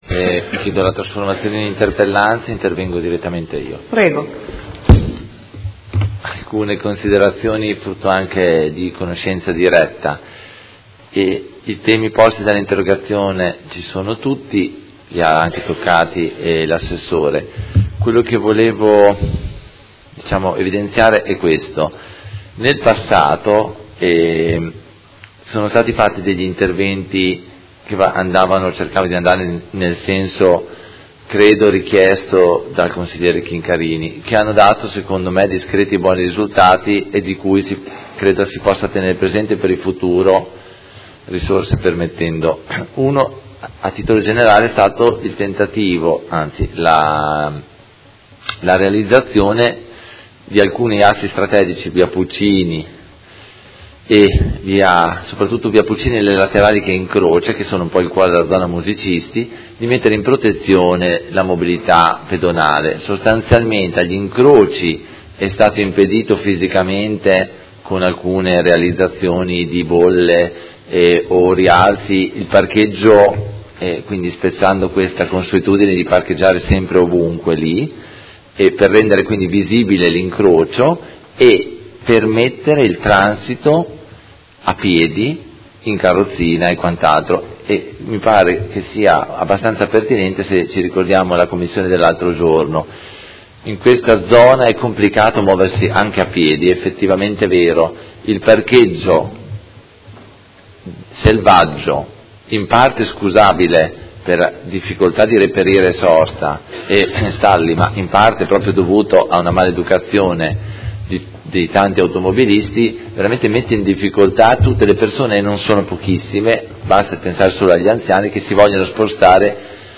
Seduta del 26/10/2017. Chiede trasformazione in interpellanza e dibattito su interrogazione del Gruppo Consiliare Per Me Modena avente per oggetto: Viabilità zona San Pio X